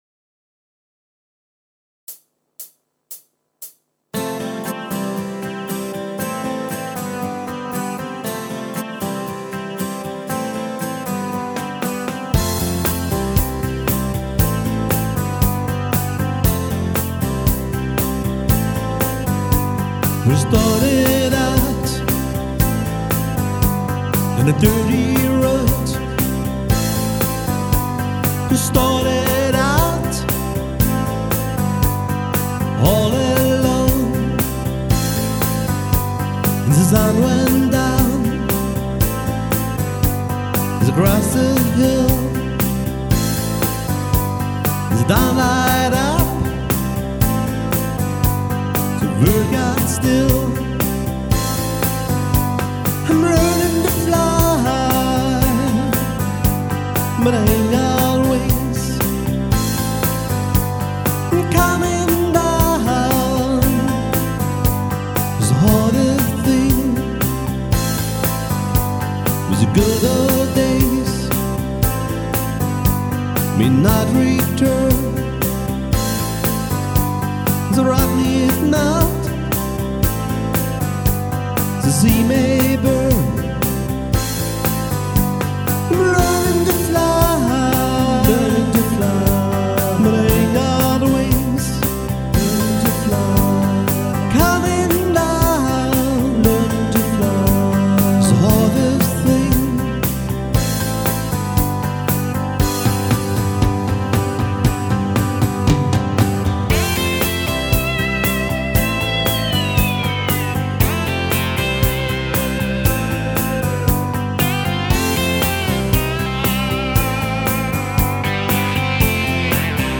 Live-Musik querbeet mit Keyboard